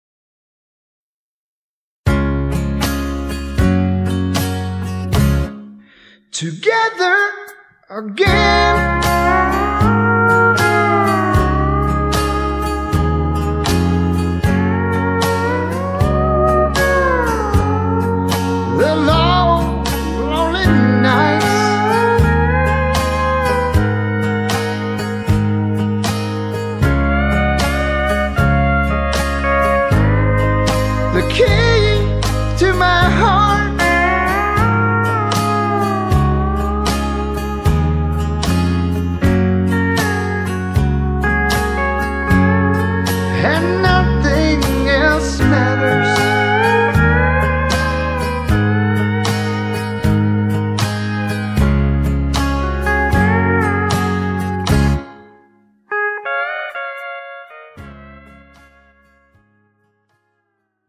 MR 반주입니다.